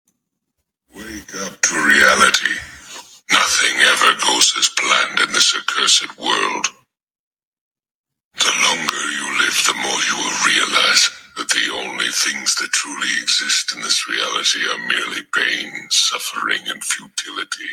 Madara Speech Wake Up To Reality